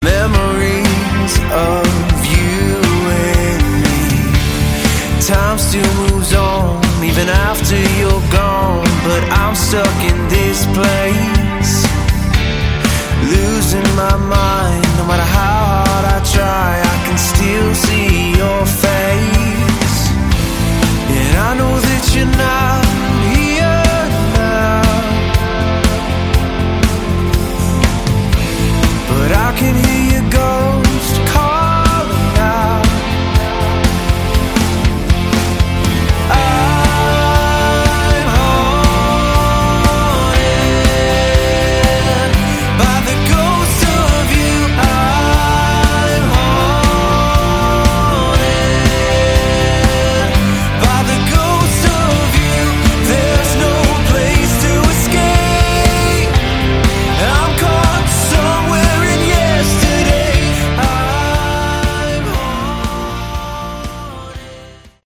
Category: Melodic Rock
Guitars, Keyboards